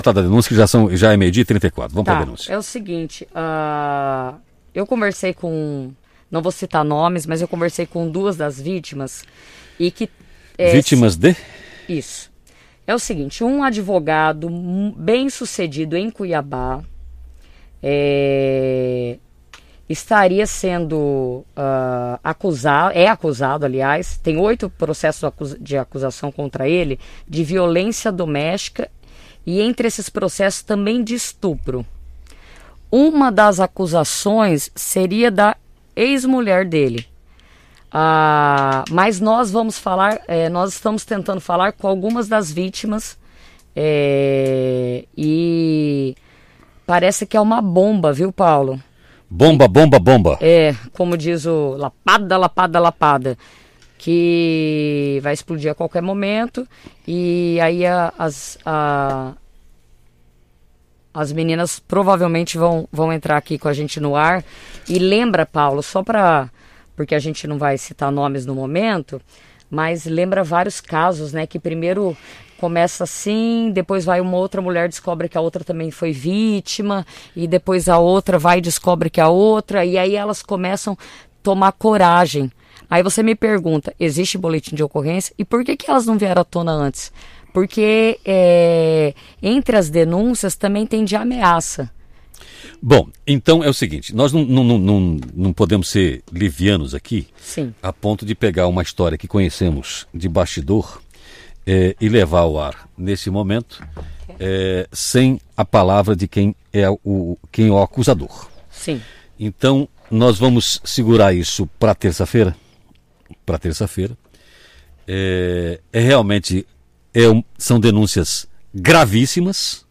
Advogado é acusado por 8 mulheres por estupro, em programa ao vivo na rádio - Hora 1 MT Notícias
Uma das vítimas participou ao vivo durante o programa e, por telefone, disse que passou por várias agressões físicas e psicológicas.